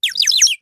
На этой странице собраны натуральные звуки чириканья птиц в высоком качестве.
Мультяшные звуки чириканья